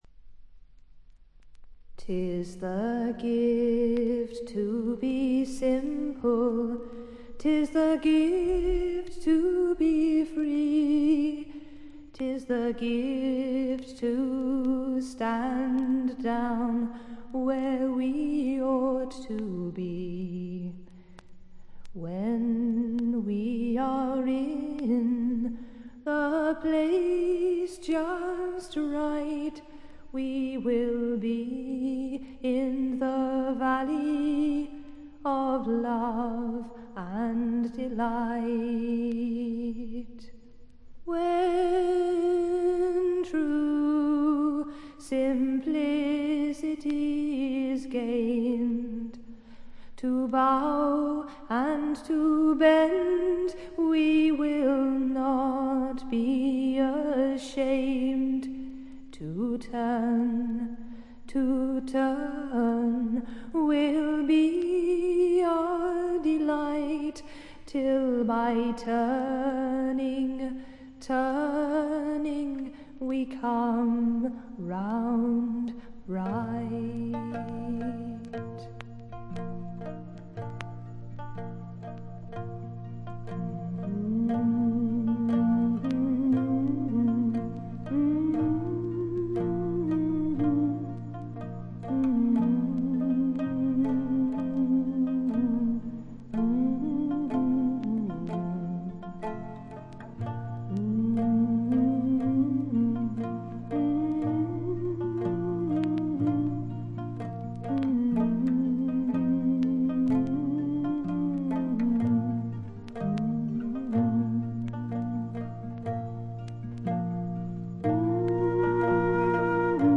静音部でバックグラウンドノイズ、ところどころでチリプチ、散発的なプツ音少し。
ところでA5は無伴奏でおごそかに始まりますが、途中から沖縄の三線としか思えない伴奏が入ってきてあせりますよ。
試聴曲は現品からの取り込み音源です。